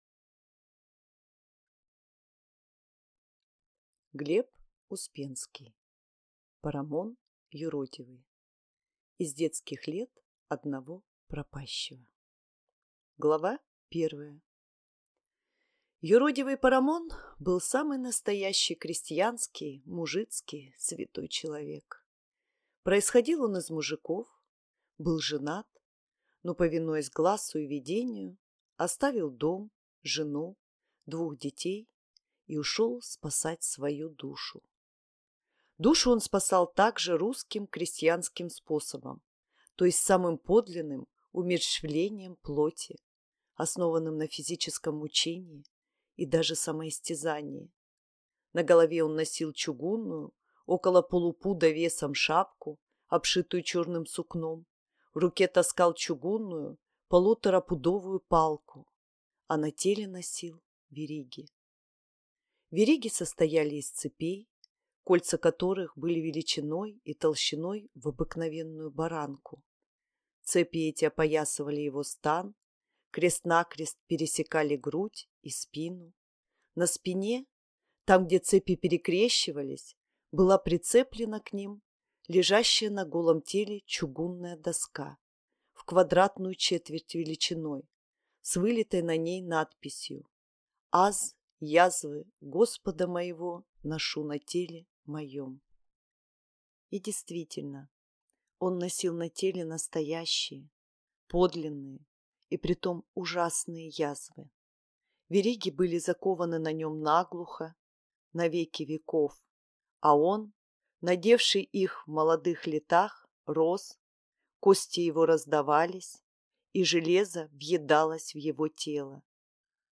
Аудиокнига Парамон юродивый | Библиотека аудиокниг